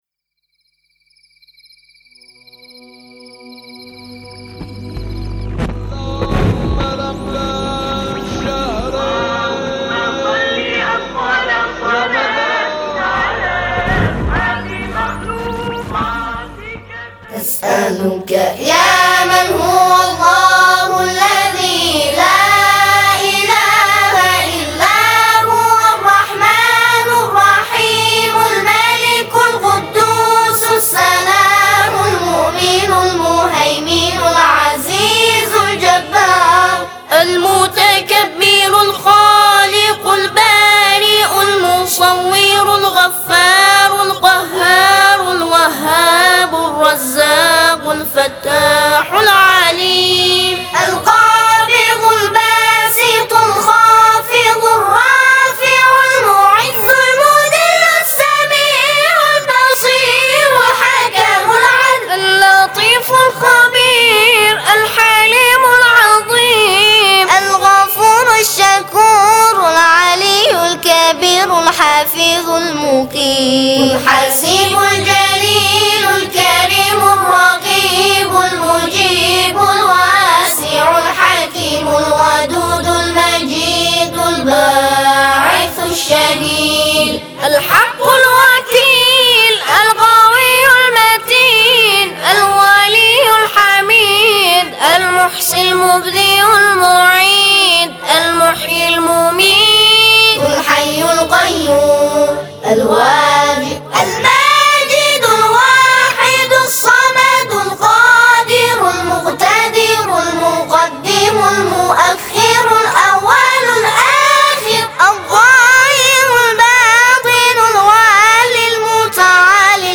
گروه تواشیح بین المللی تسنیم، در بهمن ماه سال 1392 توسط جمعی از قاریان قرآن نوجوان اصفهان پایه گذاری شد.